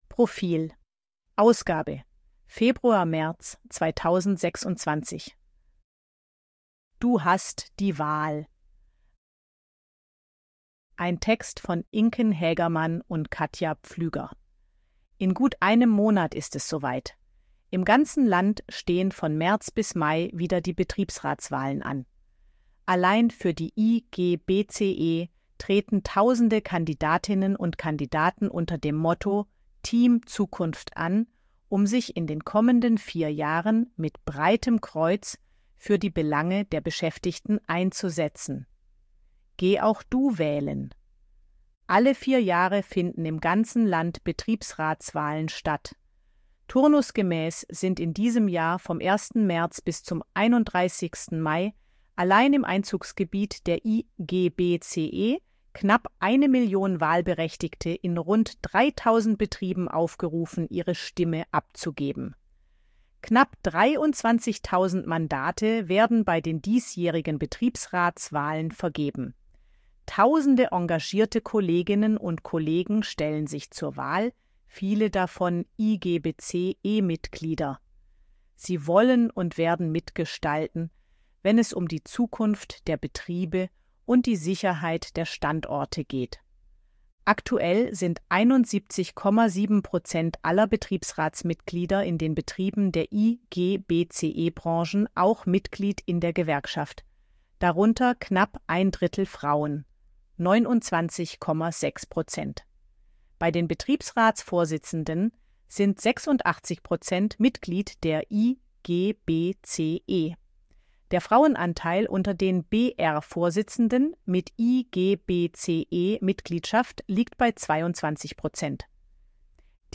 Artikel von KI vorlesen lassen ▶ Audio abspielen
ElevenLabs_261_KI_Stimme_Frau_HG-Story.ogg